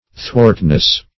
Search Result for " thwartness" : The Collaborative International Dictionary of English v.0.48: Thwartness \Thwart"ness\, n. The quality or state of being thwart; obliquity; perverseness.